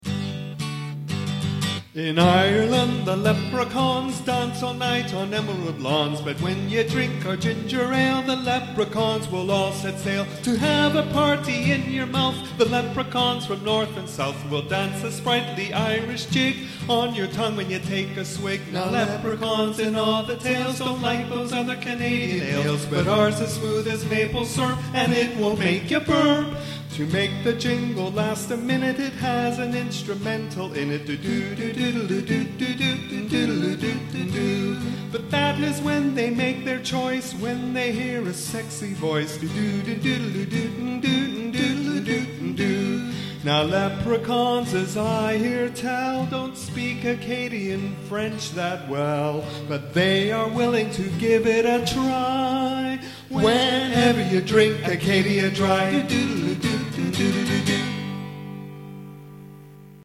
Monday, Feb. 18, 2008: One Minute Performance in Ottawa in CBC Radio One's "Canada Reads; Ontario Rocks" Concert
I performed my winning entry for the CBC Radio One show Bandwidth's "Ginger Ale Jingle Contest" on stage with various Ontario musicians who had composed songs linked to the five novels of the "Canada Reads Contest".